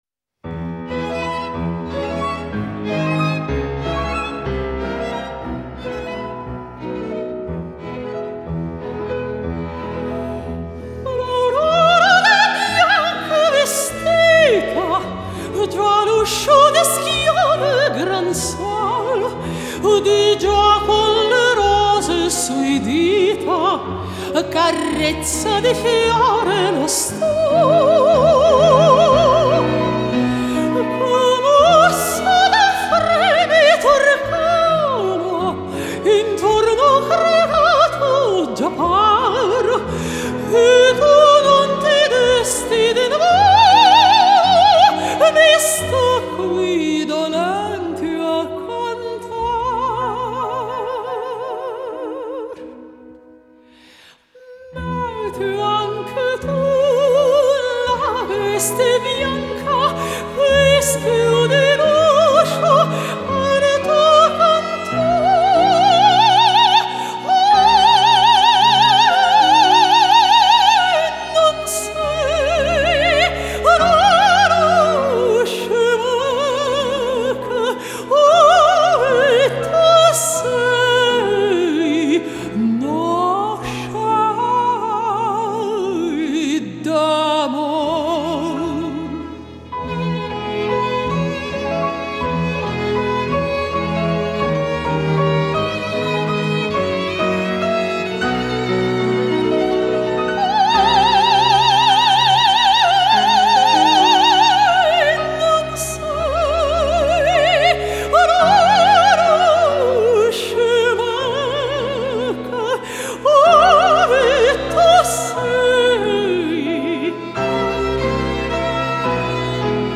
Романсы